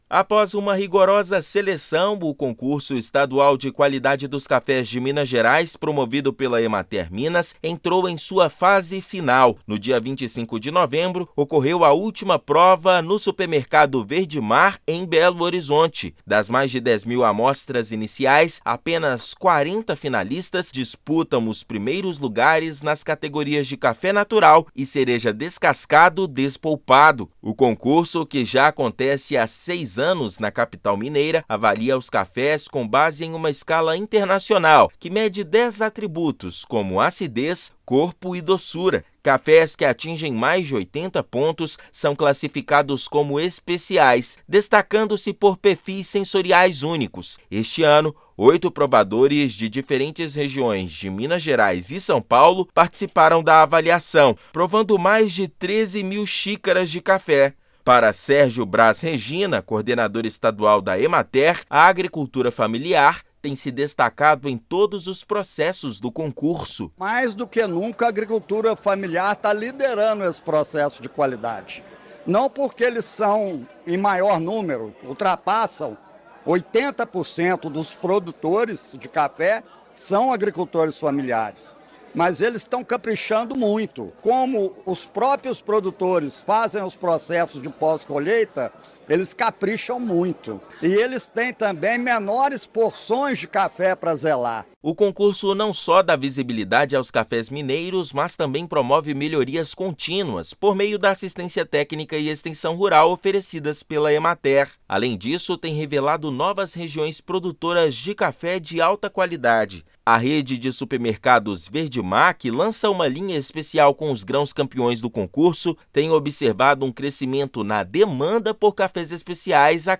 Julgamento dos grãos finalistas aconteceu na capital mineira. Ouça matéria de rádio.